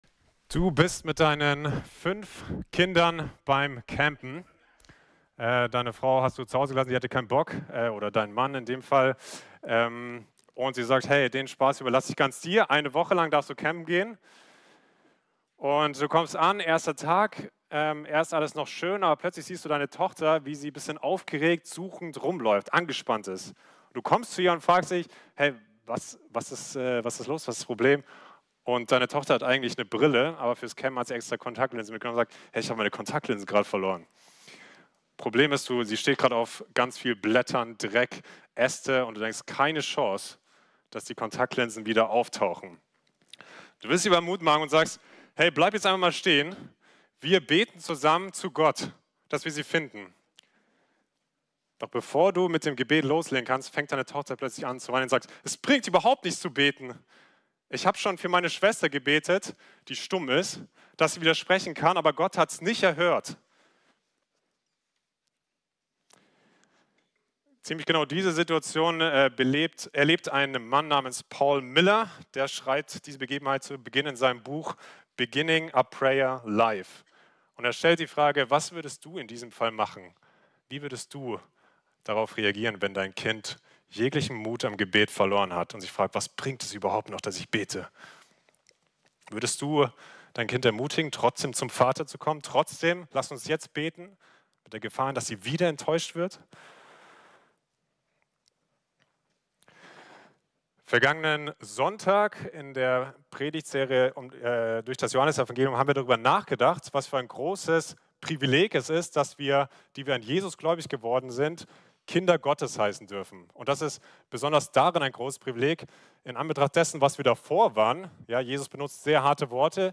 FeG München Mitte Predigt Podcast